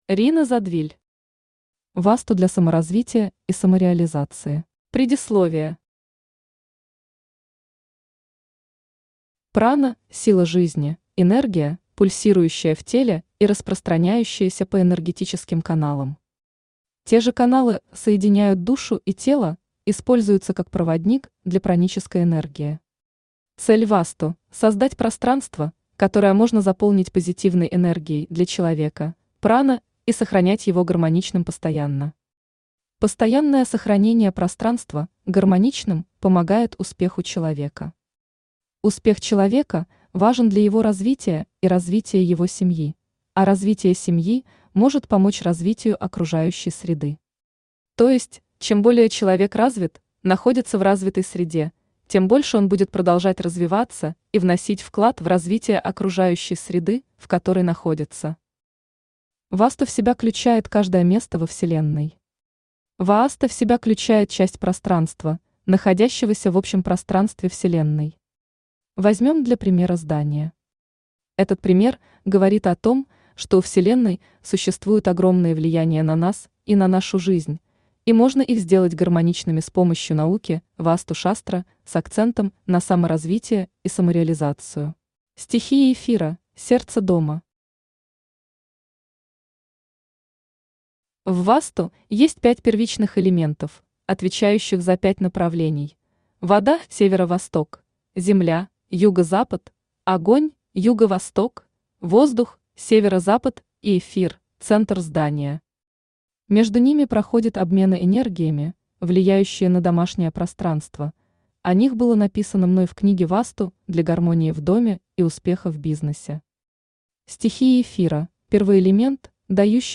Аудиокнига Васту для саморазвития и самореализации | Библиотека аудиокниг
Aудиокнига Васту для саморазвития и самореализации Автор Рина Задвиль Читает аудиокнигу Авточтец ЛитРес.